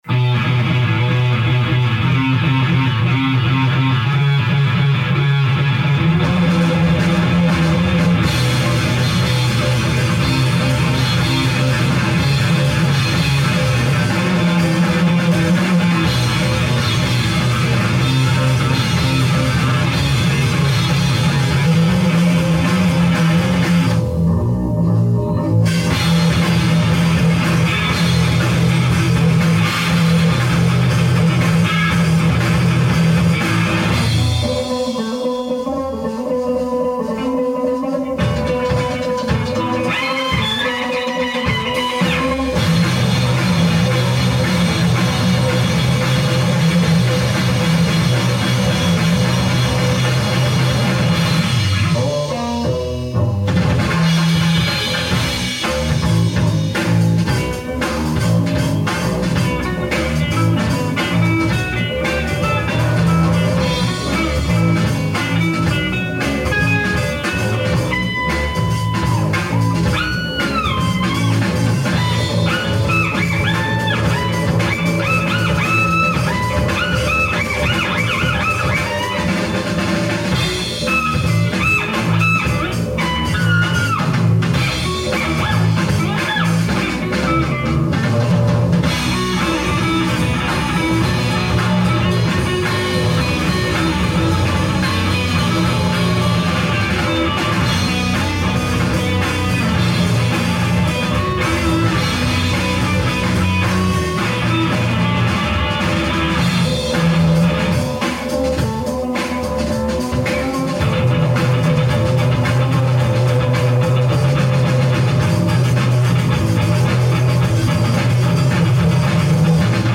making of - November 2005